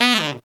Index of /90_sSampleCDs/Zero-G - Phantom Horns/TENOR FX 2